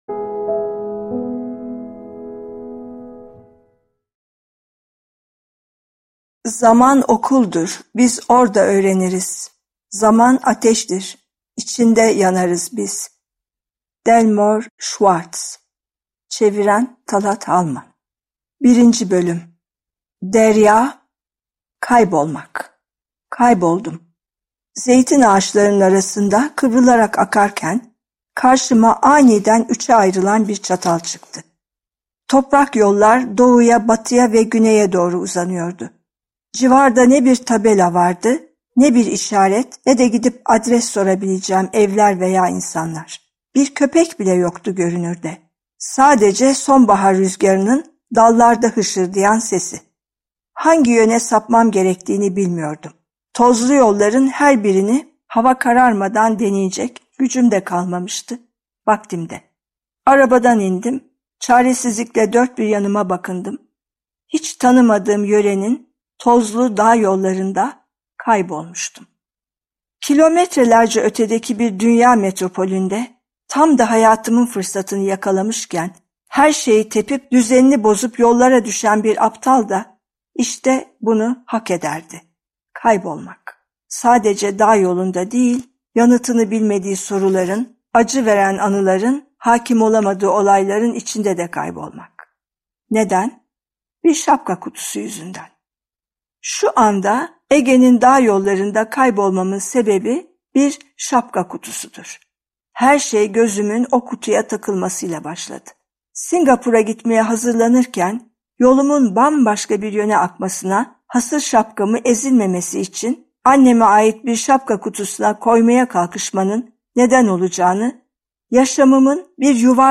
Seslendiren
AYŞE KULİN